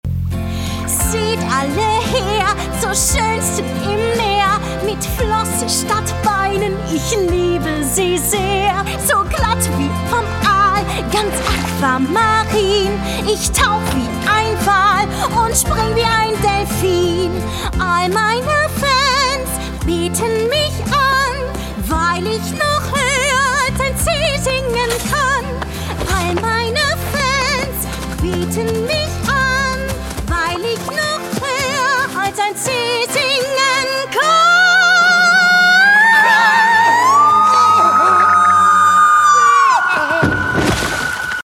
Werbung - Manhattan Multitasker